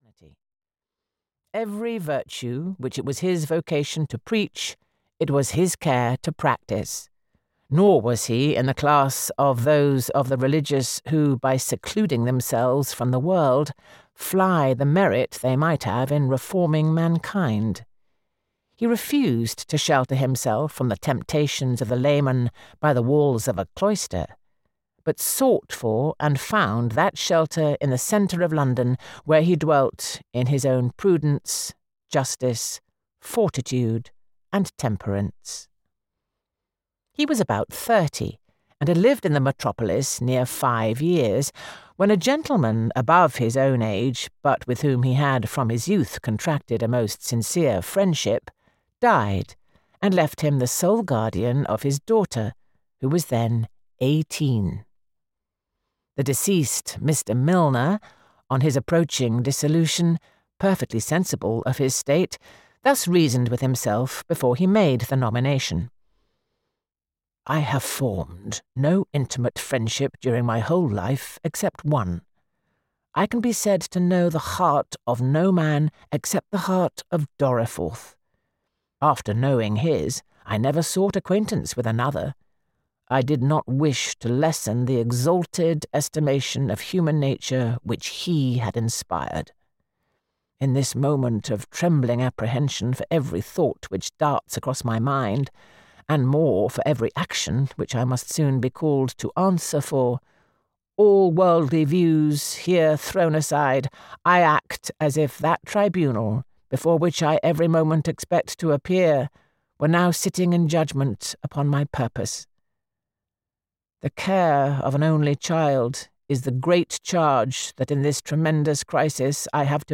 A Simple Story (EN) audiokniha
Ukázka z knihy
Here it is given a delightful reading by the acclaimed actress Juliet Stevenson.
• InterpretJuliet Stevenson